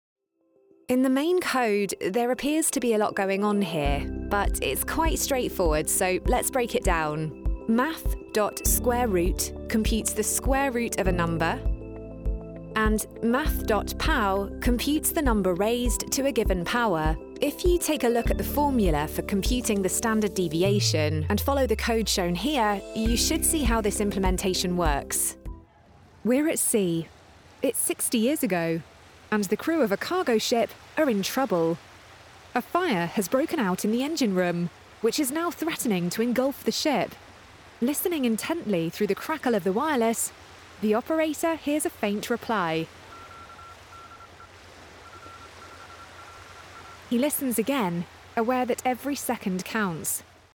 English (British)
E-learning
Bright, warm, fresh, natural and professional are just some of the ways my voice has been described.